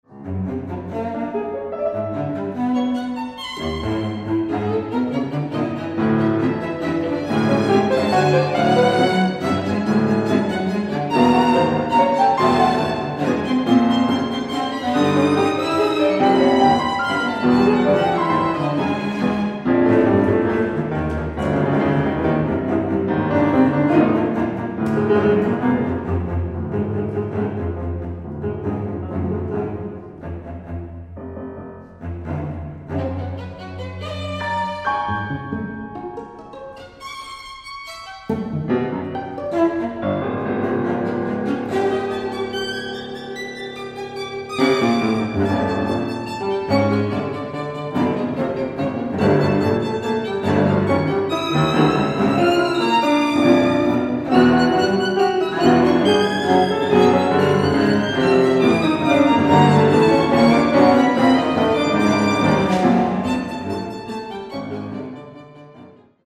violin, Cello, Piano
the cello starting the playful and sinister dance of death